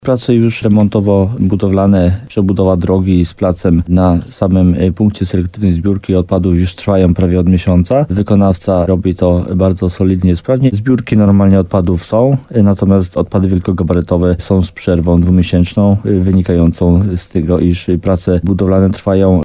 Przebudowy wymagał tu przede wszystkim plac, ale też droga dojazdowa – mówił wójt Jacek Migacz w programie Słowo za Słowo na antenie RDN Nowy Sącz.